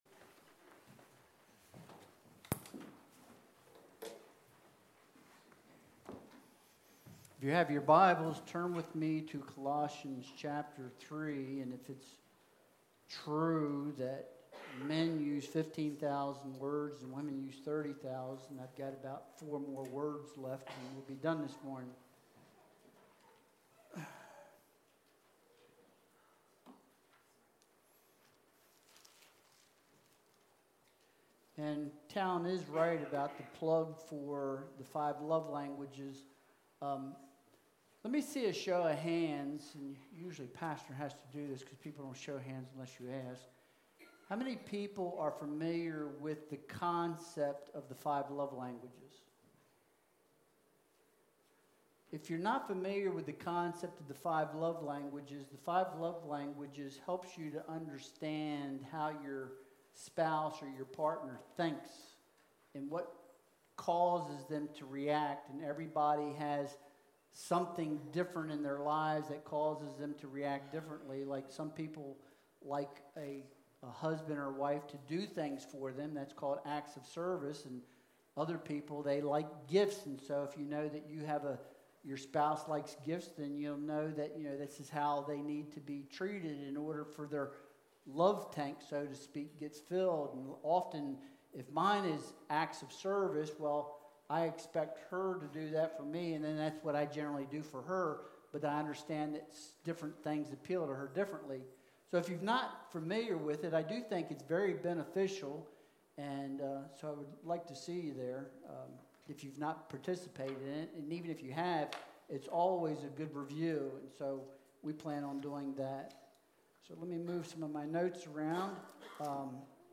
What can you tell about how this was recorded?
Colossians 3.5-4.1 Service Type: Sunday Worship Service Download Files Bulletin « Evidence and Oneness of Belonging Above